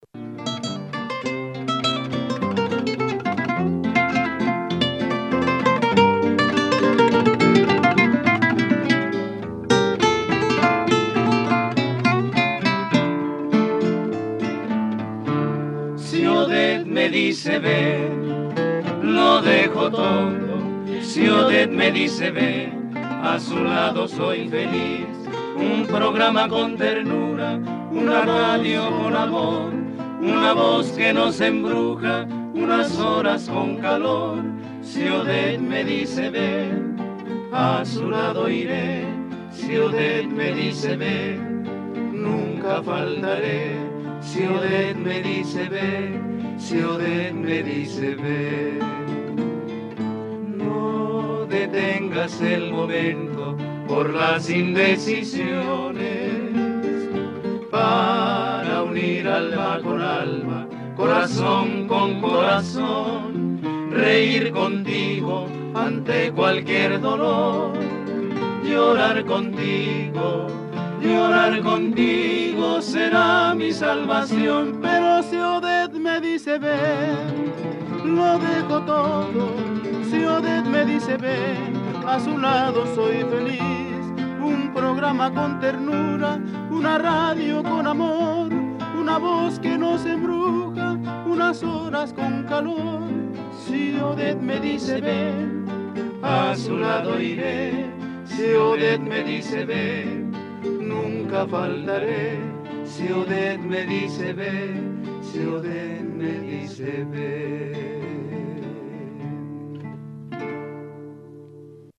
Cançó identificativa del programa